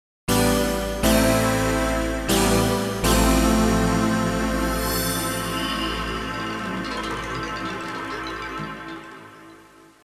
Заежженый как старая бразильская проститутка патч от d50 digital native dance чистенький , и с смоделированным конвертером E -mu Emulator 2 ) сразу аналох то прет какой )?))